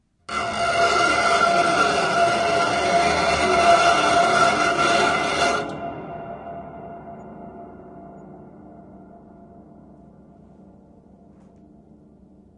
SCRAPE S钢琴音板样本 " scrapes01
用2个mxl 990话筒进行录音，一个靠近琴弦，另一个在8英尺以外。这些都是立体声录音，但一个通道是近处的话筒，另一个是远处的话筒，所以为了获得最佳效果，可能需要进行一些相位和平移调整。
Tag: 音响 效果 FX 恐怖 工业 钢琴 音响 声音效果 音板